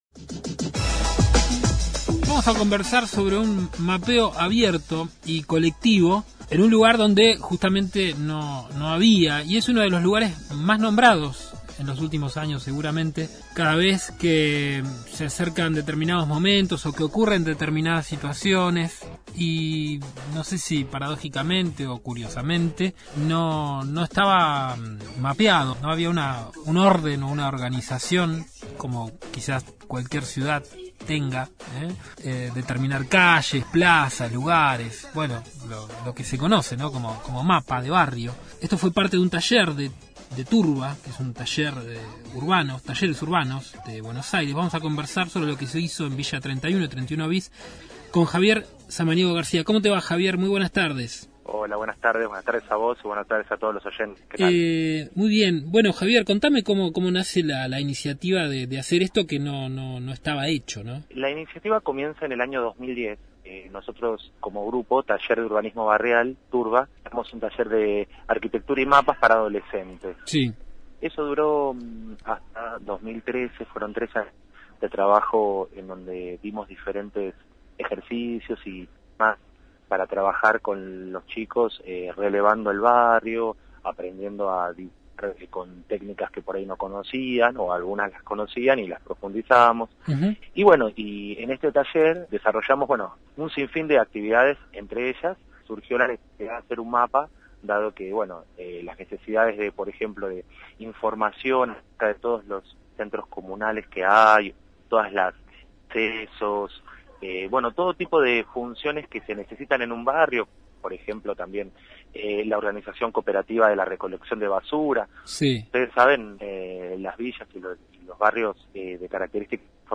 Fuera de Sector, FM Universidad Lunes a viernes de 16 a 18hs